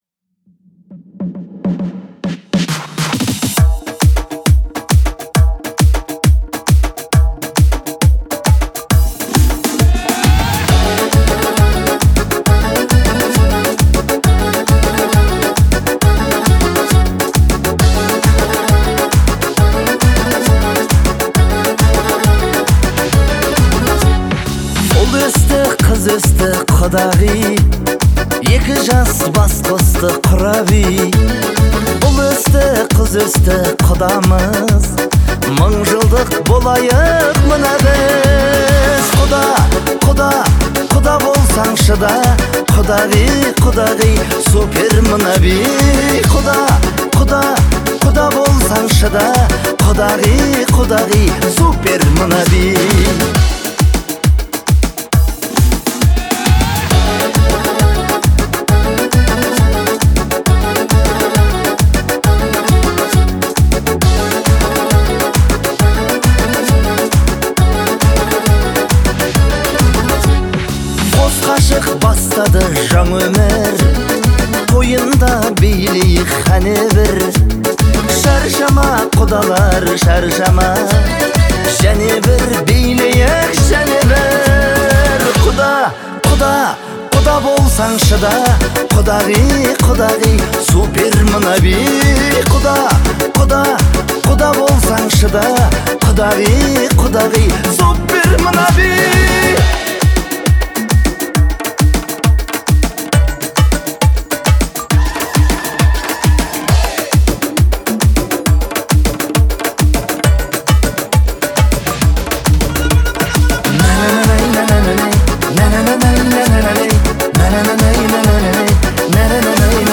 относится к жанру казахской народной музыки и фолка
Музыка отличается мелодичностью и душевным исполнением